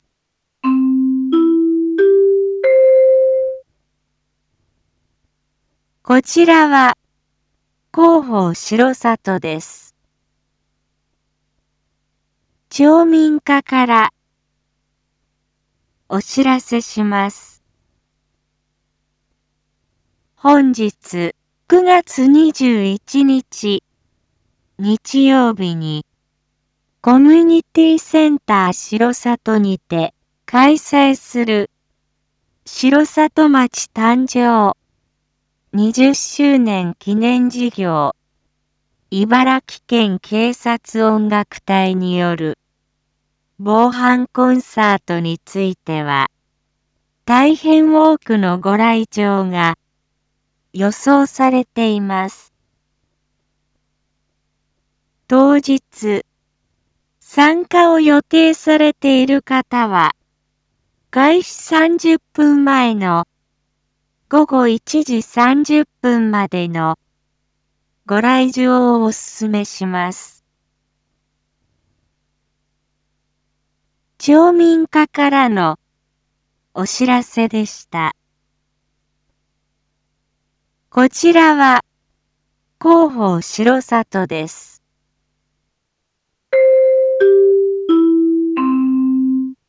一般放送情報
Back Home 一般放送情報 音声放送 再生 一般放送情報 登録日時：2025-09-21 09:01:31 タイトル：茨城県警察音楽隊による防犯コンサート② インフォメーション：こちらは広報しろさとです。